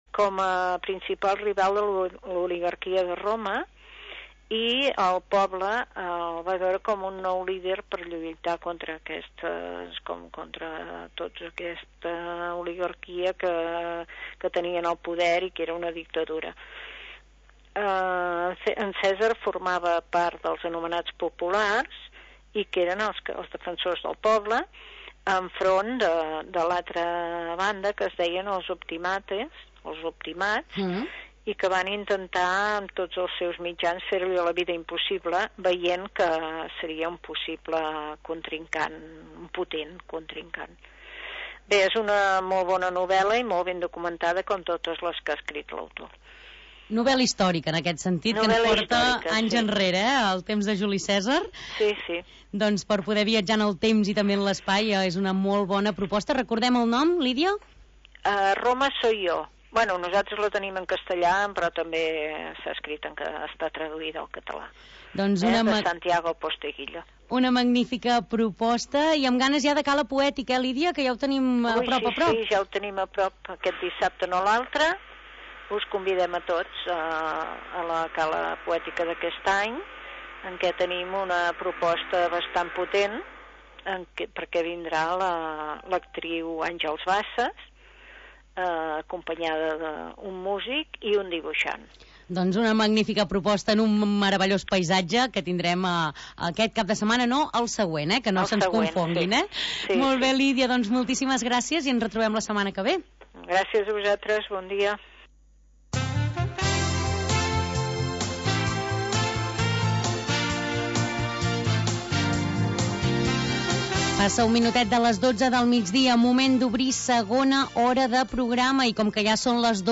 Un magazín d'entreteniment en temps de confinament.